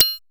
Modular Perc 06.wav